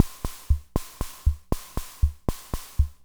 Index of /90_sSampleCDs/300 Drum Machines/FG Enterprises King Beat/FG Enterprises King Beat Sample Pack_Audio Files
FG Enterprises King Beat Sample Pack_Loop5.wav